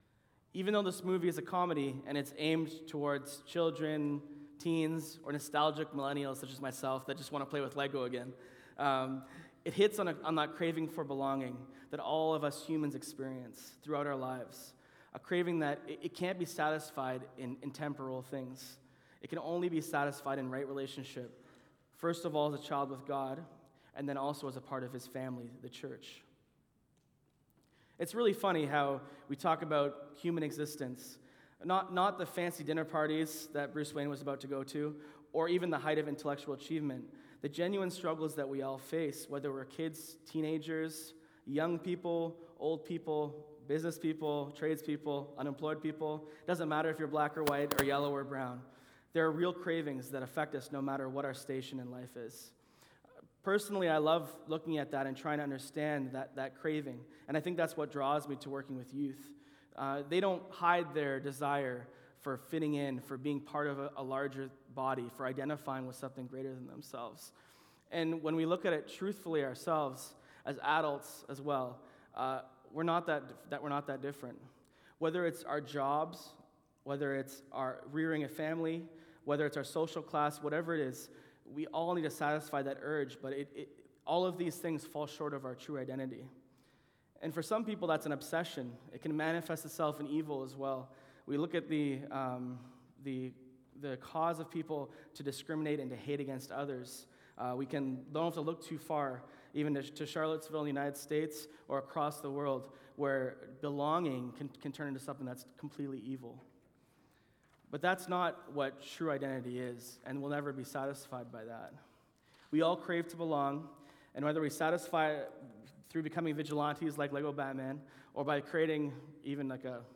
Sermons | Bramalea Baptist Church